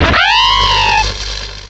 pokeemerald / sound / direct_sound_samples / cries / luxio.aif